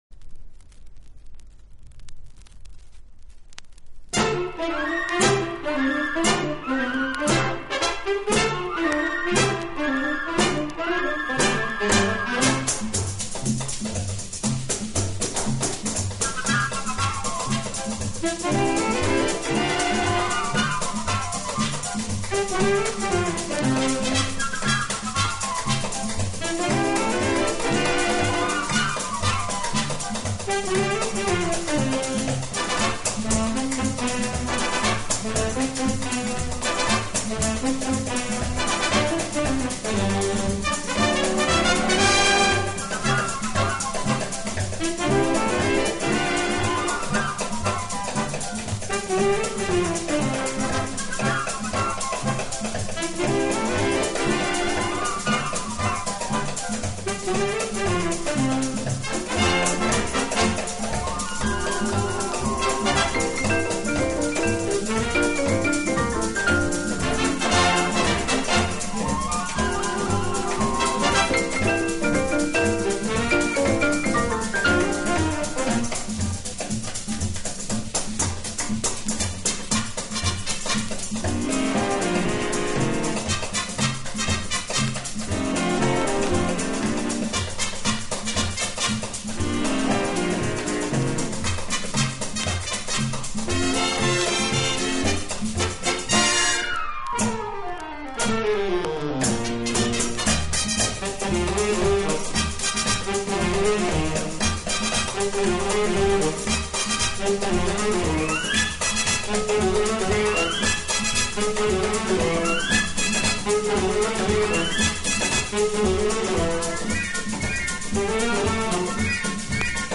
【轻音乐】
演奏以轻音乐和舞曲为主。